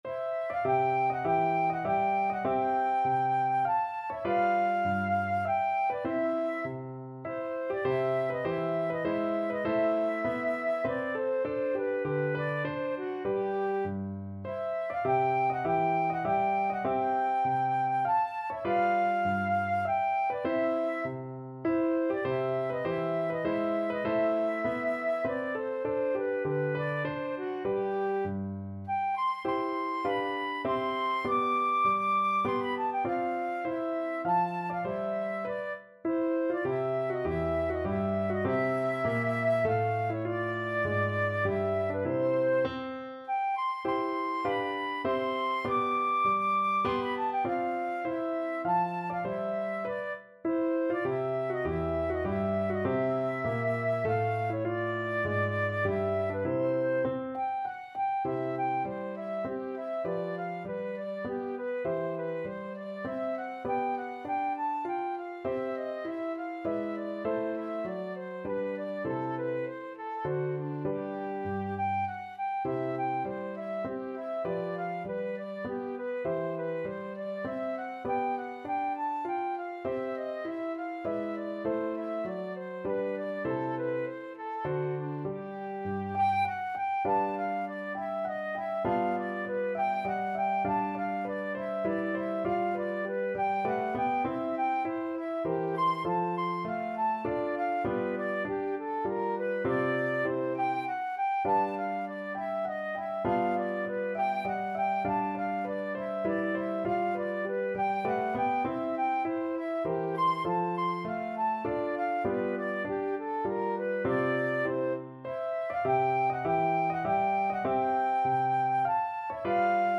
Flute version
3/4 (View more 3/4 Music)
Flute  (View more Easy Flute Music)
Classical (View more Classical Flute Music)